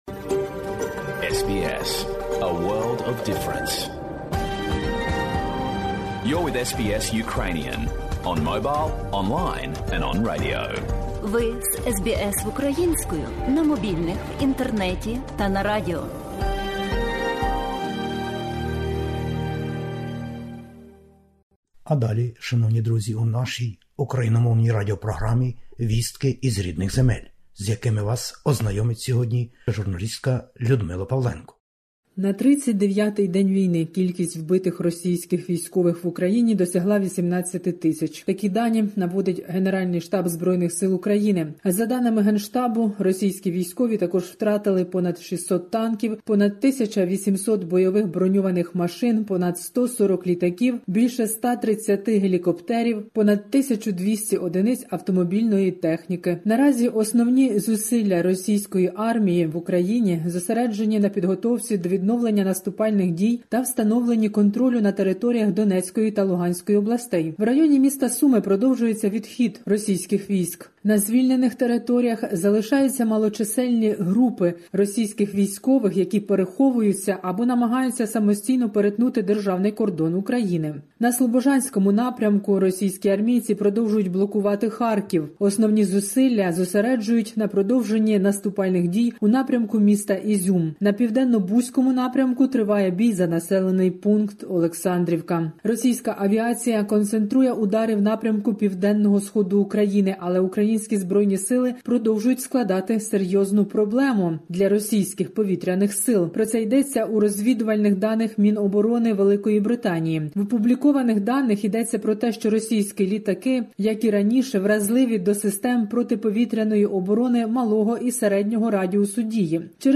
Добірка новин із воюючої України.